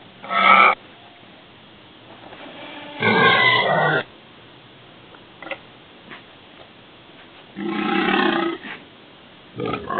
Звук свиньи, которая хрюкает